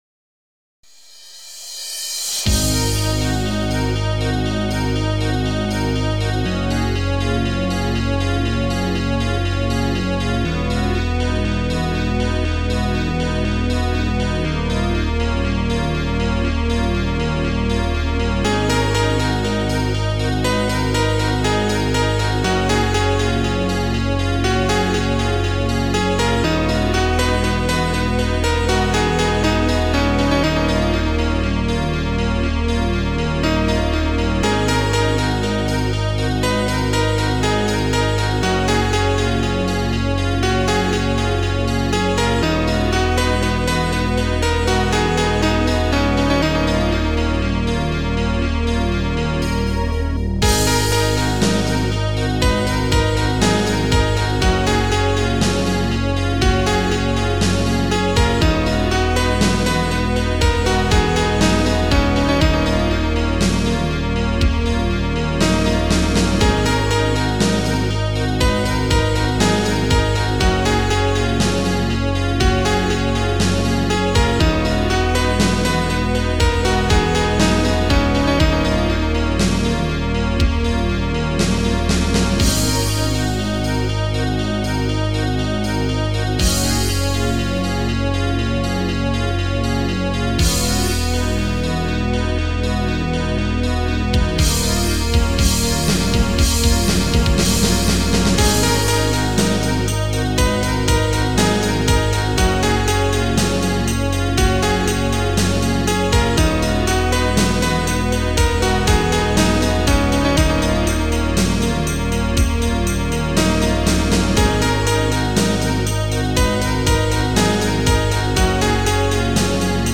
s3m (Scream Tracker 3)
Piano 16
Ton basovy 9
Buben 18
Cinel dlouhy 7
Cinel revers 1